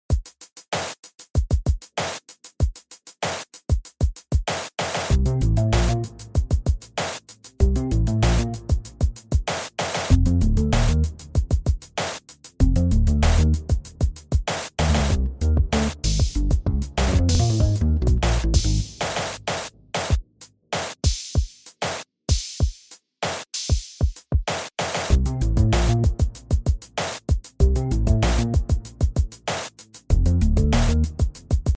Retro Song Cover